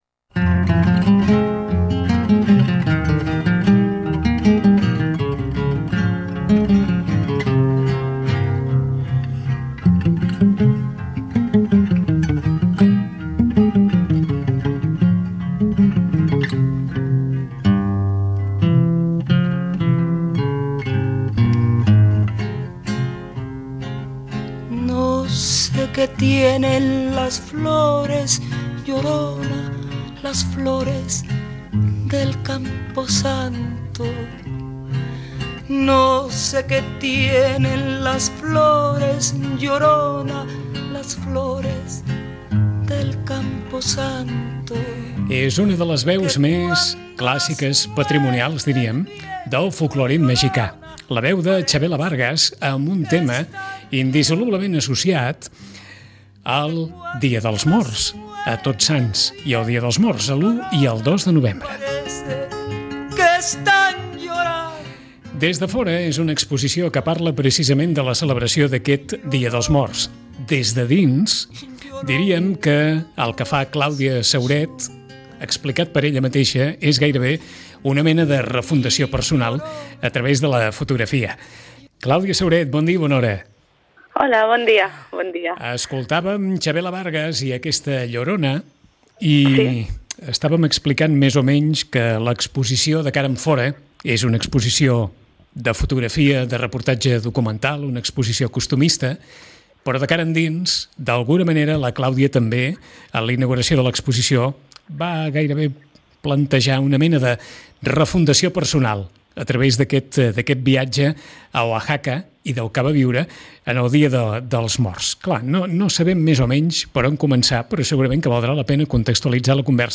Una conversa en la qual no només parlem de fotografia.